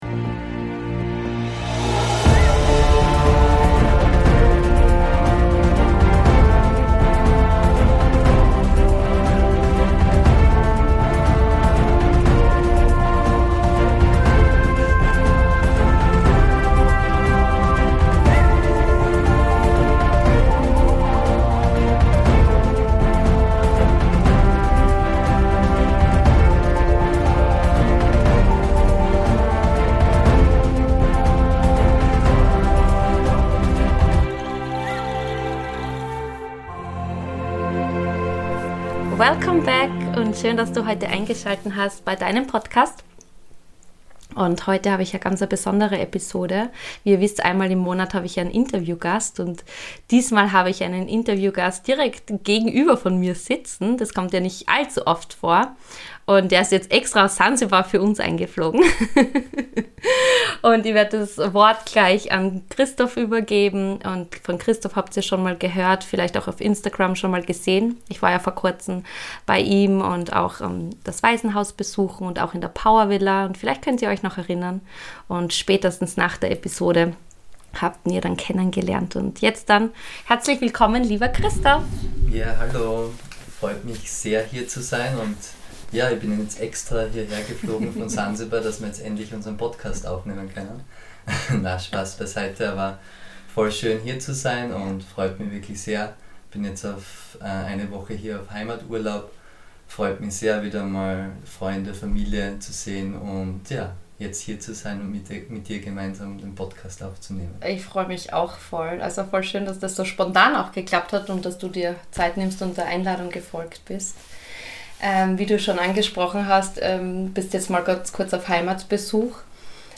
#10 Live your inner Power - Interview